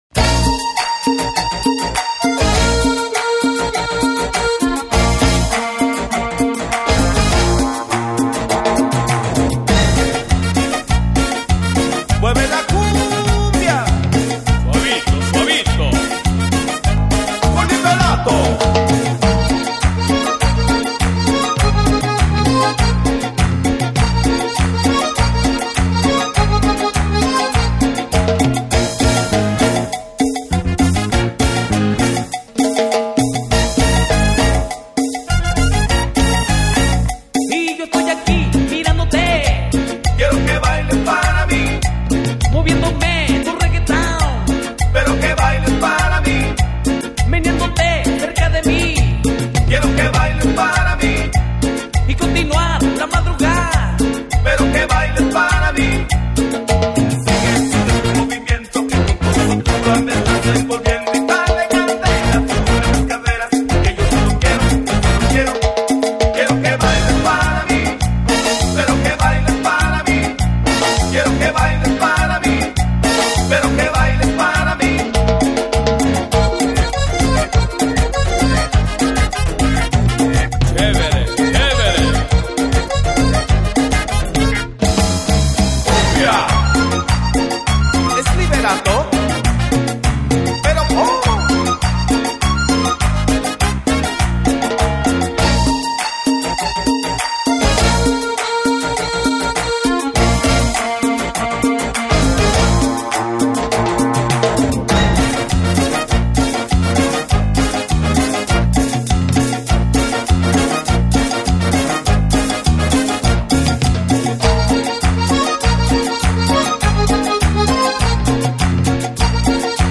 Güiro y coros.
Saxofón.
Trompeta.
Congas.
Bajo.
Baterista.